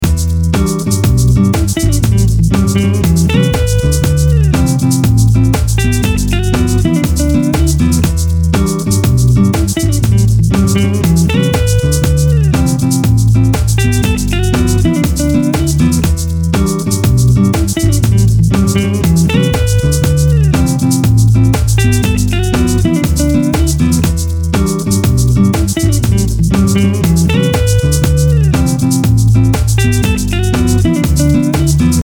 latin neo-soul tracks